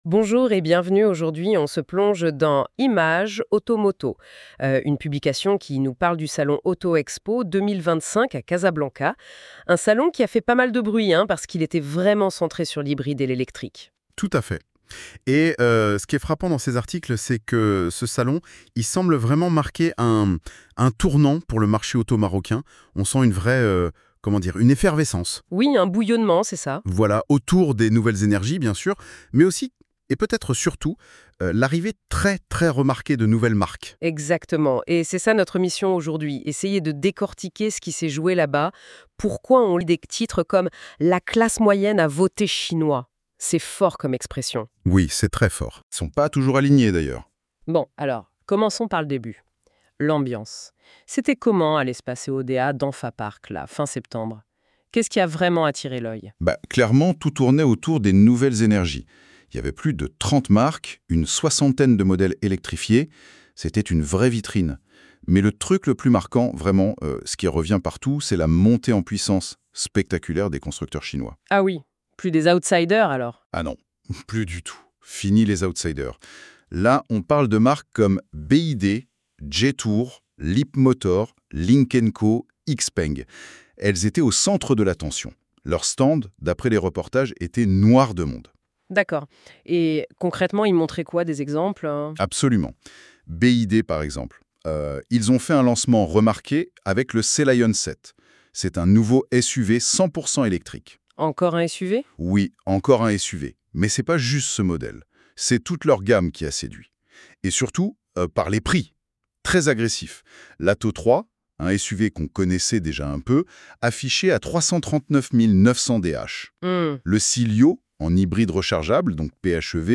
Podcast - débat LODJ I-MAG Spécial Auto Expo 2025.mp3 (14.83 Mo)